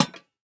Minecraft Version Minecraft Version snapshot Latest Release | Latest Snapshot snapshot / assets / minecraft / sounds / block / lantern / place3.ogg Compare With Compare With Latest Release | Latest Snapshot